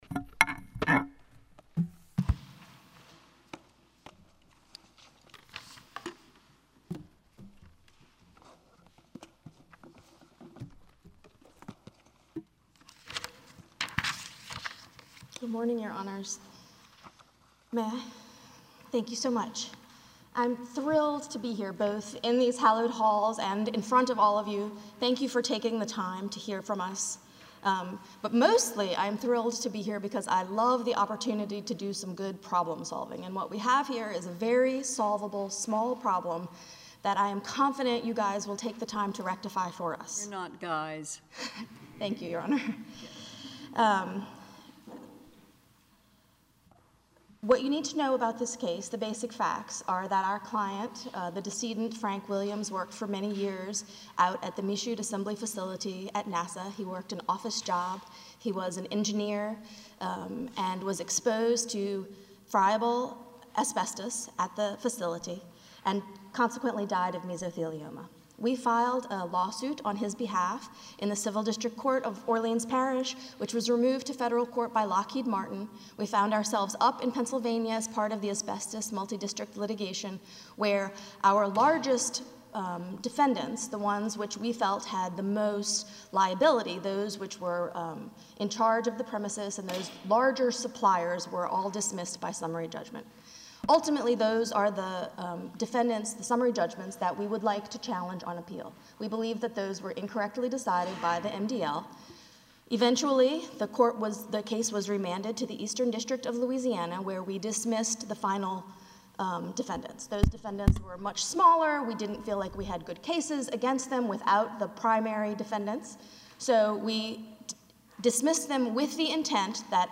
Oral argument at the Fifth Circuit.